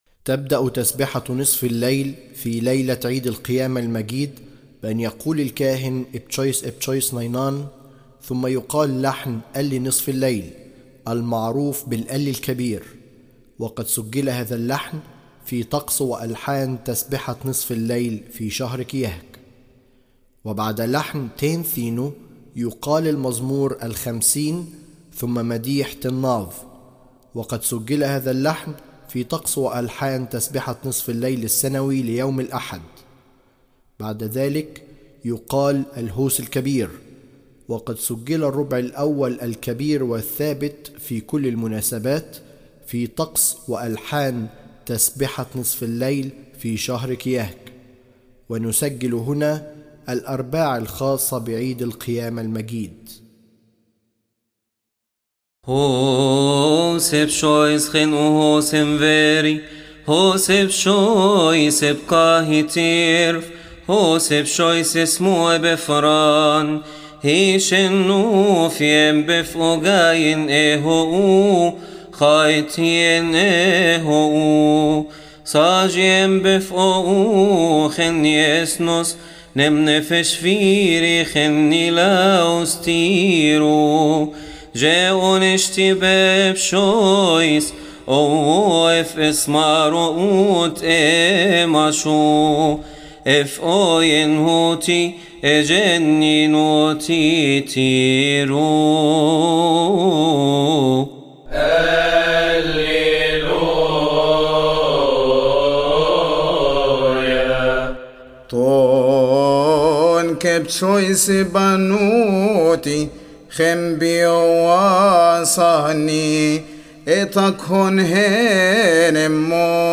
لحن طونك ابتشويس بانووتي